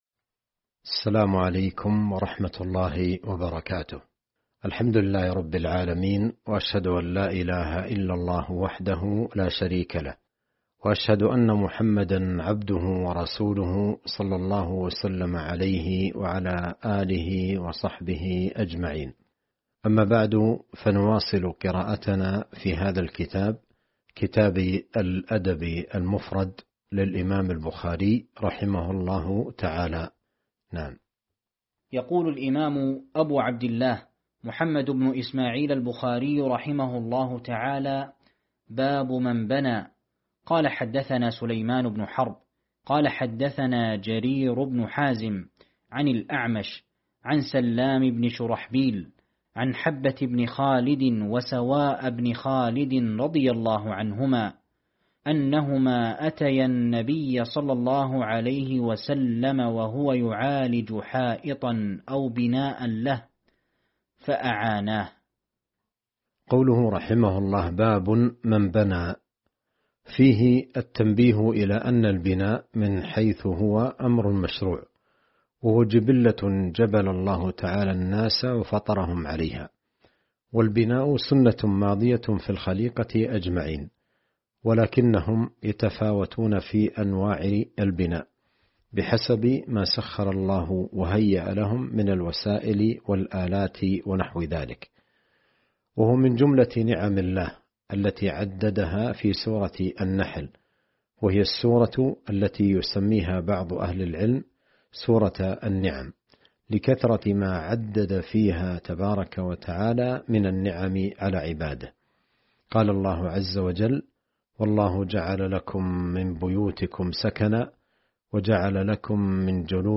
شرح الأدب المفرد الدرس 142